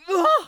traf_damage9.wav